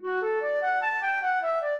flute-harp
minuet2-2.wav